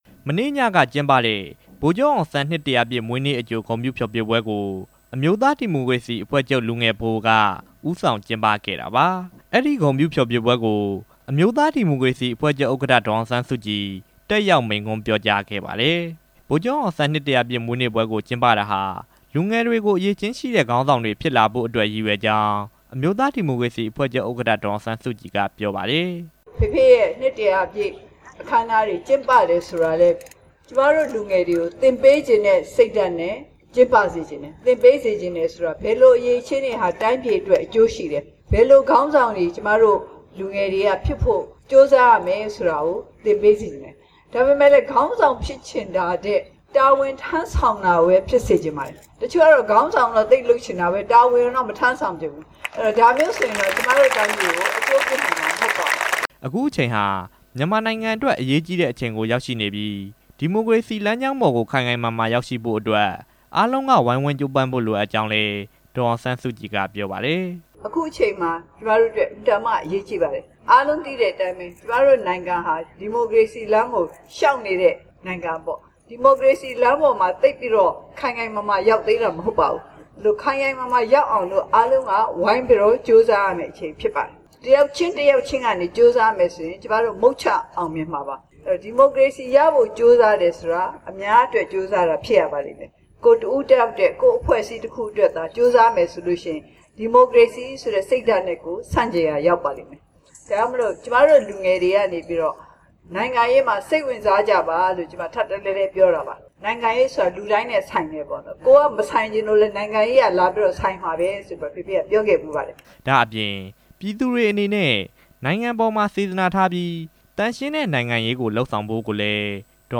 လာမယ့်နှစ် ဖေဖေါ်ဝါရီလ ၁၃ ရက်နေ့မှာကျရောက်မယ့် ဗိုလ်ချုပ်အောင်ဆန်း နှစ် (၁ဝဝ) ပြည့် မွေးနေ့အကြို ဂုဏ်ပြုဖျော်ဖြေပွဲကို မနေ့က ရန်ကုန်မြို့ ပြည်သူ့ဥယာဉ်မှာ ကျင်းပခဲ့ပါတယ်။ အမျိုးသား ဒီမိုကရေစီအဖွဲ့ချုပ် ဥက္ကဋ္ဌ ဒေါ်အောင်ဆန်းစုကြည်၊ အမျိုးသား ဒီမိုကရေစီအဖွဲ့ချုပ် နာယက ဦးတင်ဦးတို့ တက်ရောက်မိန့်ခွန်းပြော ကြားခဲ့သလို သီးလေးသီး အငြိမ့်အဖွဲ့က သီချင်း၊ ပြဇာတ်တွေနဲ့ ဖျော်ဖြေခဲ့ပါတယ်။